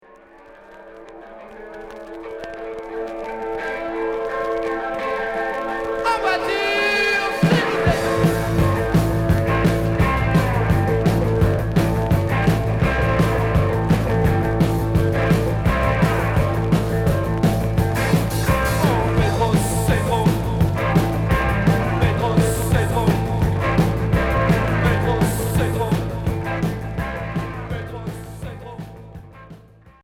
(live)
Rock